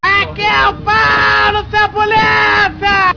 explodebmb_wtt.wav